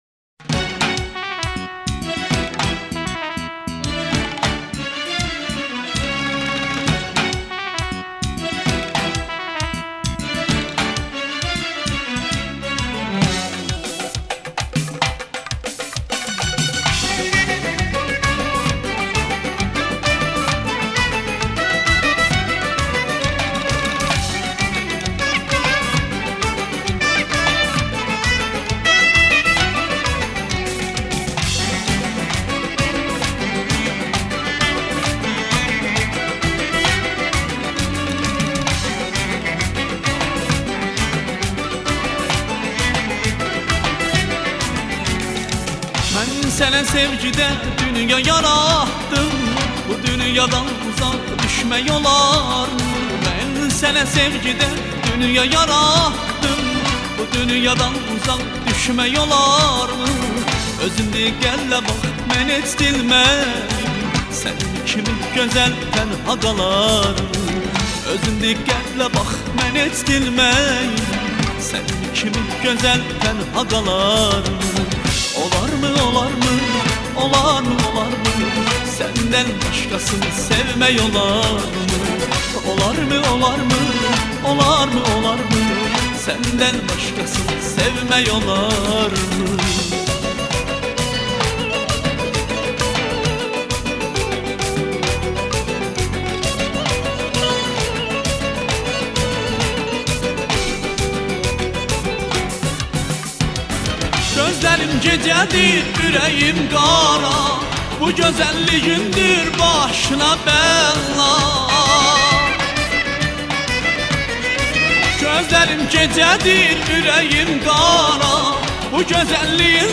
موسیقی آذری
آلات موسیقی آذری :